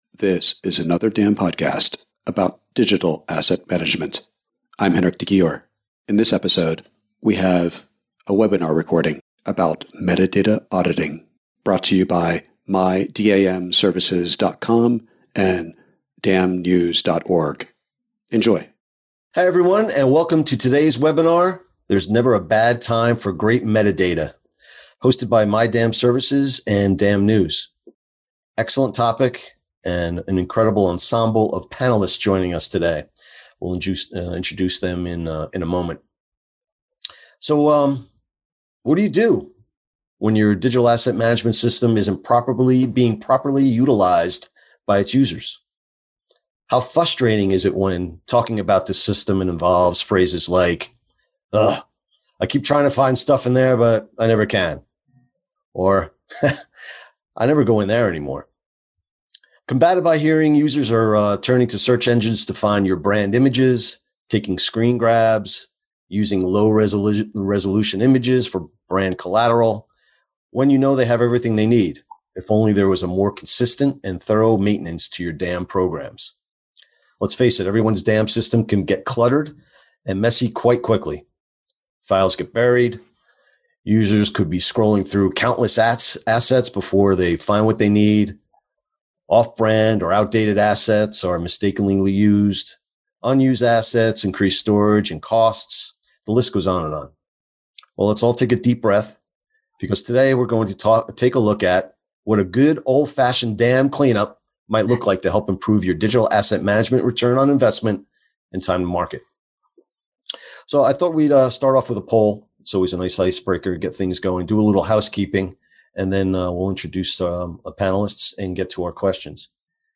metadata-auditing-webinar.mp3